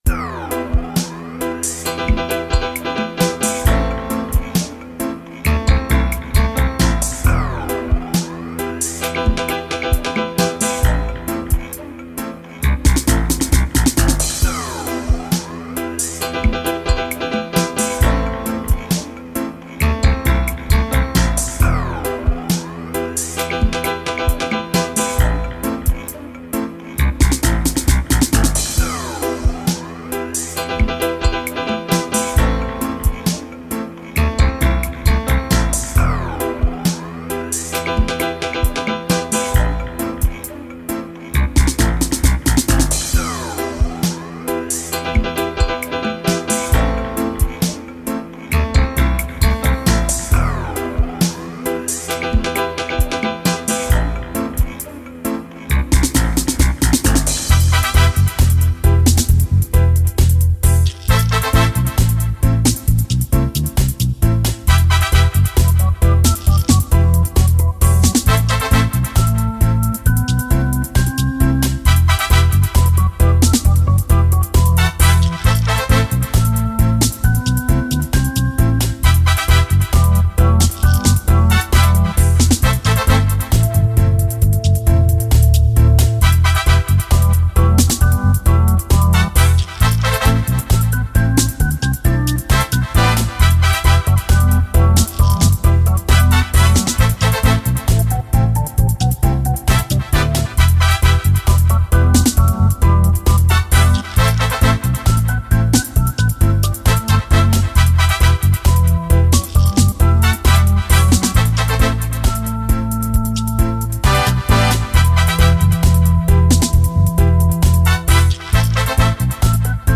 A compilation mix of long time dub mixes now out .